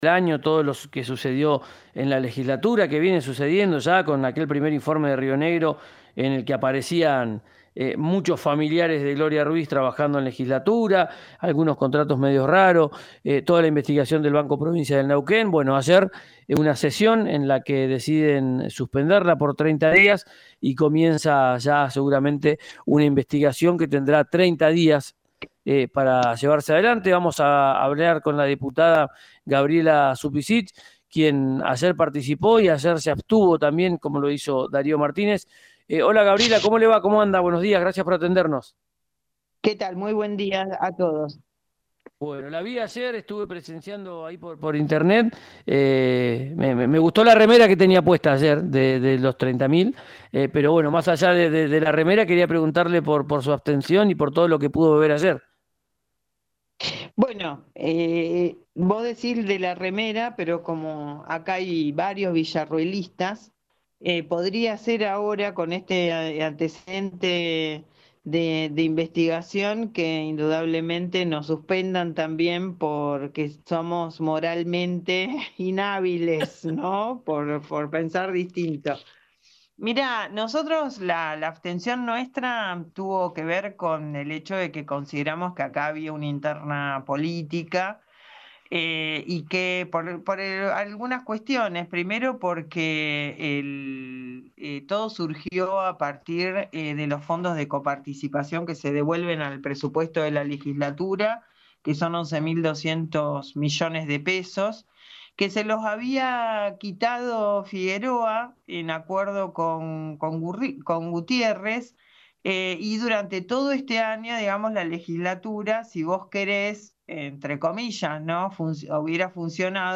Escuchá a la diputada provincial Gabriela Suppicich en RÍO NEGRO RADIO